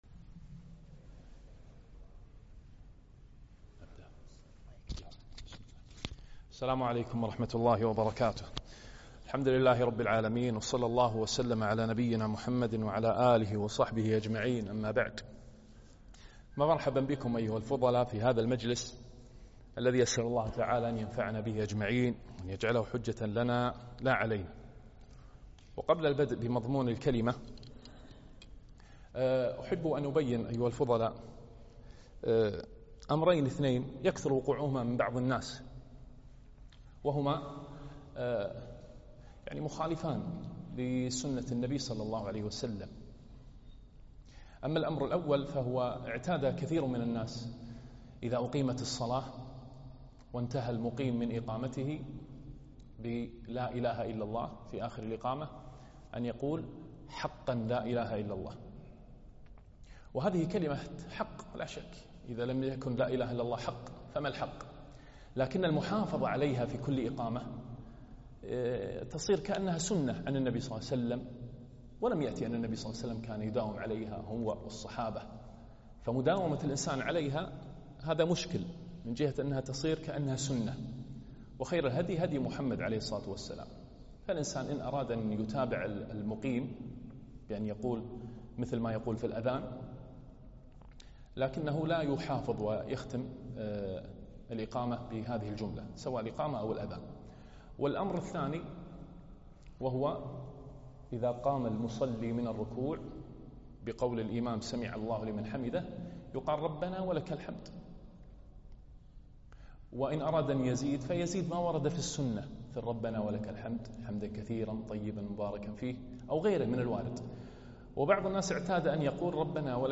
محاضرة - فوائد من قصة يوسف عليه السلام